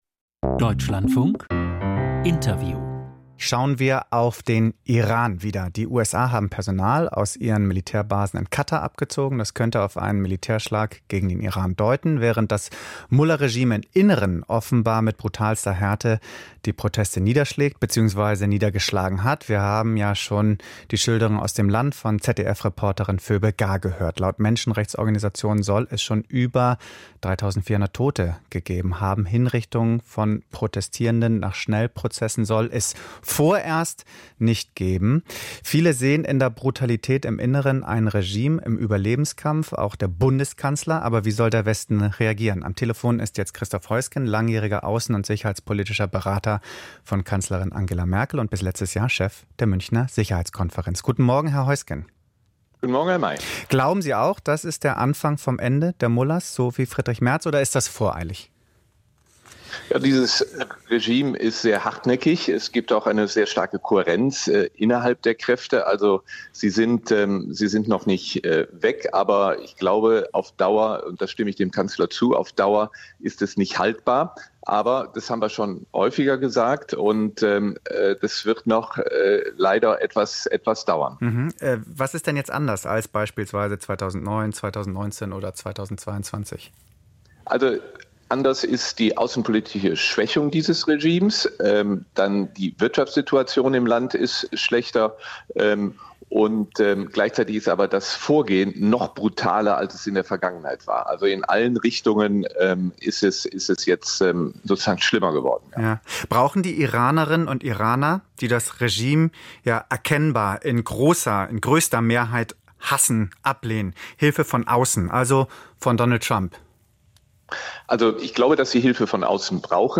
Iran-Politik - Interview mit Christoph Heusgen, Sicherheitsberater